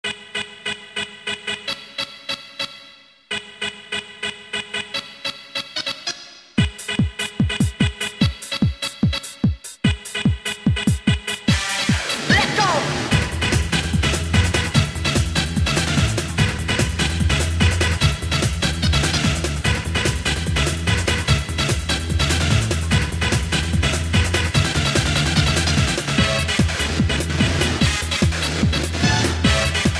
Digital Stereo Techno-Rave Cyber-Delic Audio Sound Tracks